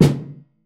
metallic snare.ogg